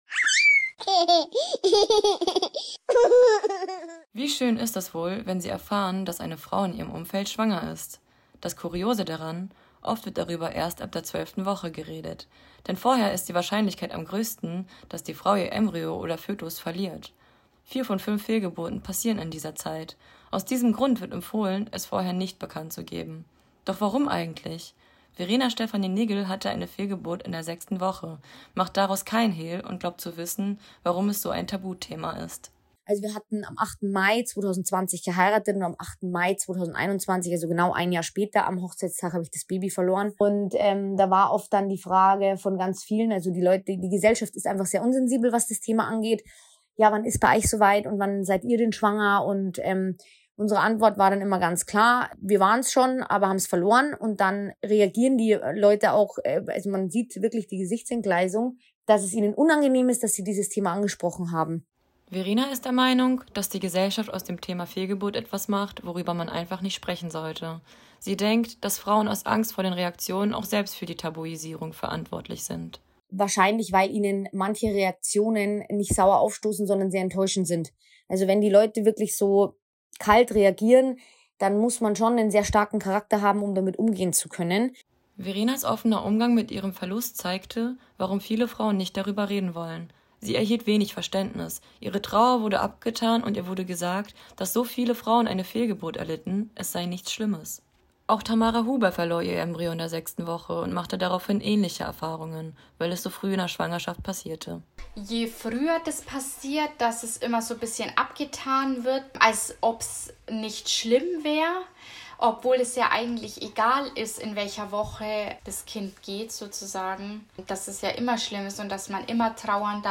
Doch kaum jemand redet über den traurigen Fall der Fehlgeburt. Zwei Betroffene erklären, warum das so schwer fällt.